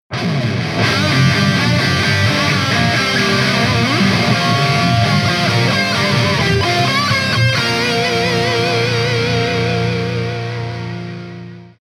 Na oboch mikrofonoch je HiPass filter nastaveny na 100Hz.
Toto su ukazky len gitar: